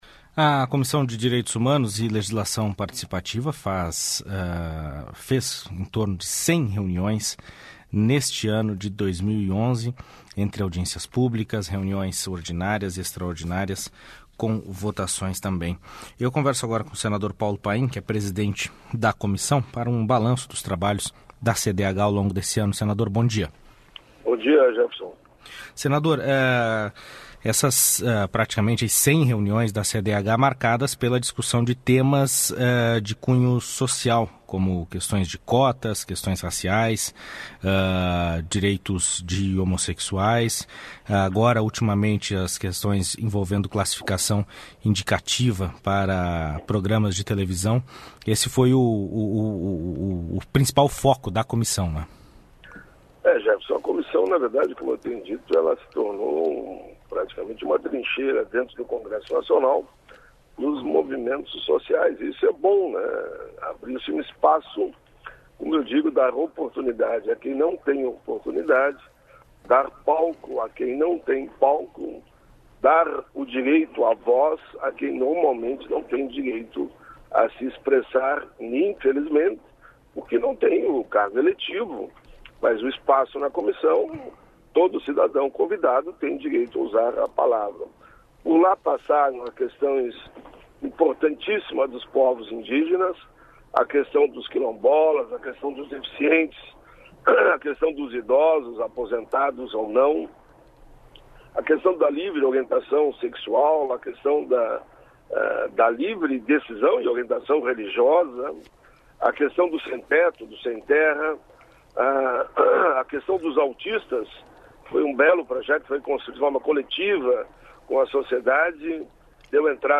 Entrevista com o presidente da CDH.